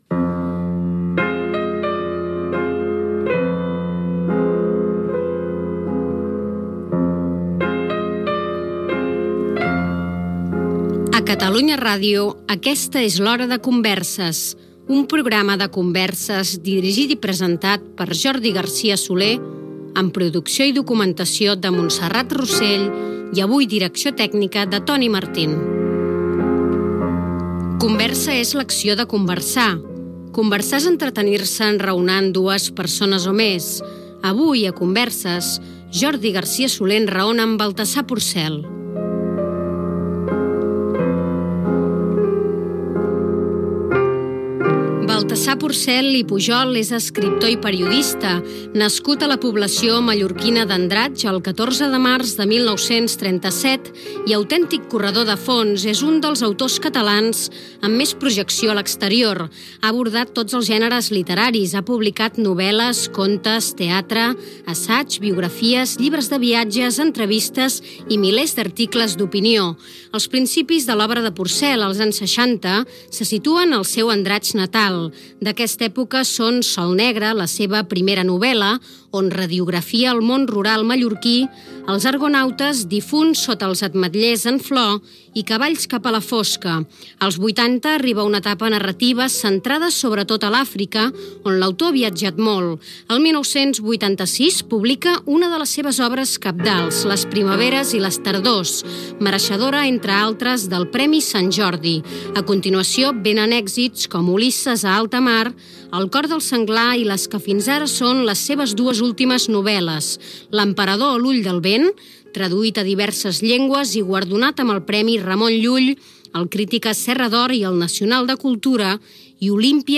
Careta del programa, perfil biogràfic i entrevista a l'escriptor Baltasar Porcel: la seva infància a Mallorca, la Guerra Civil espanyola, el gènere de la novel·la